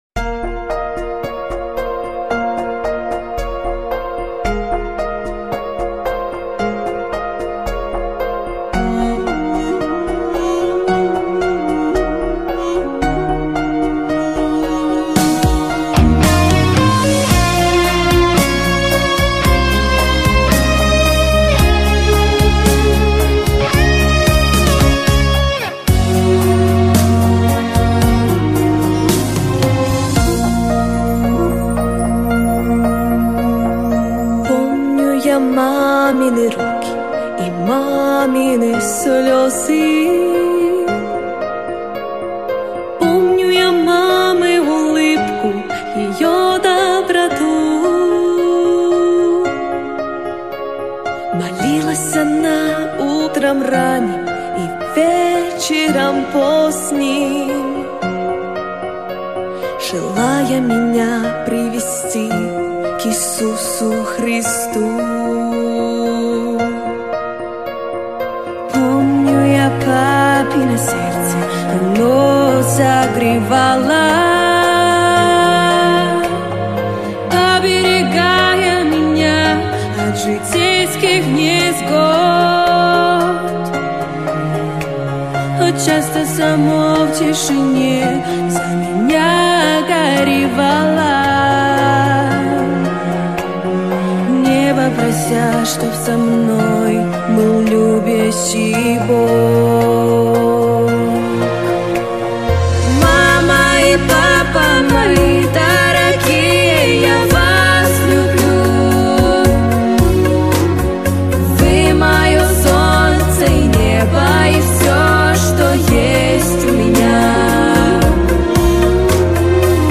• Категория: Детские песни
христианские песни